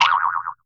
BoingCartoon HB01_14_5.wav